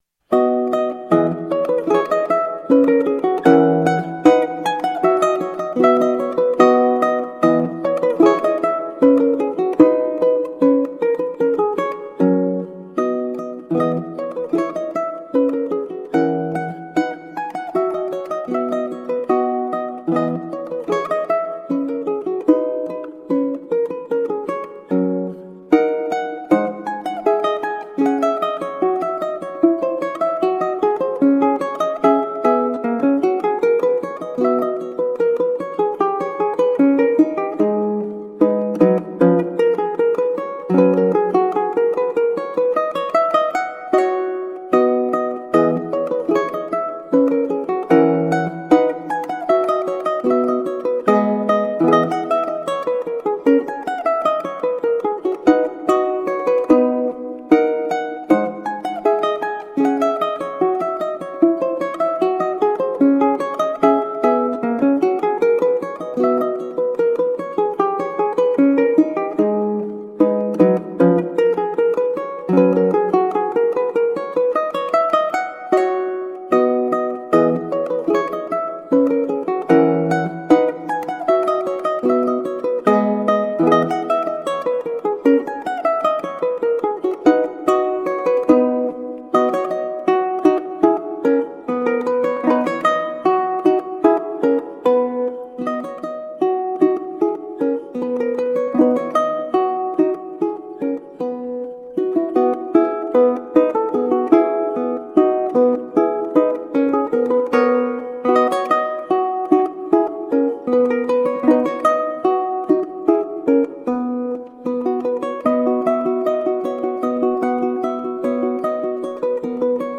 performed on a tenor ukulele
Classical, Baroque, Instrumental